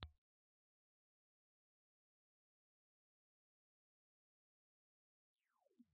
Cardioid
Impulse Response File:
IR file of the GEC BCS2280 dynamic microphone.
GEC_BCS2280_IR.wav
This design is very much like a reversed speaker used as a dynamic microphone.